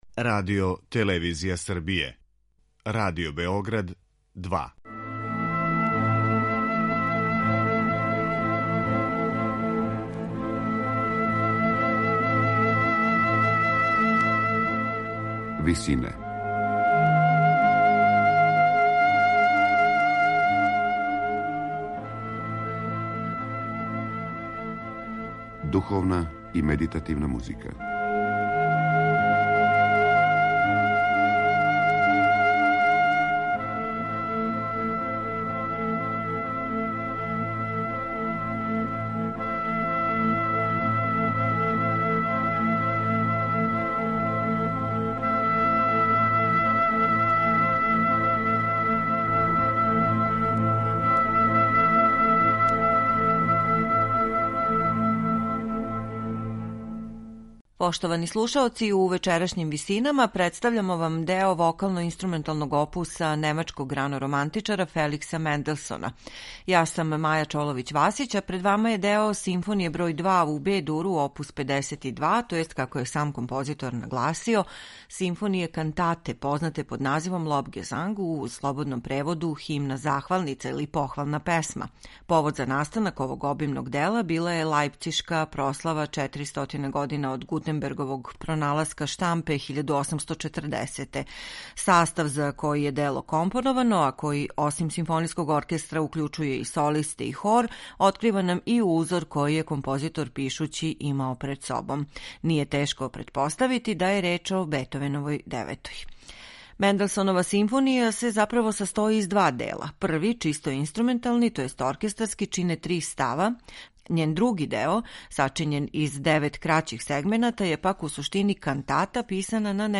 Други део композиције је заправо кантата за симфонијски оркестар, солисте и хор, која се састоји из девет краћих сегмената.
Лондонским симфонијским оркестром диригује Клаудио Абадо.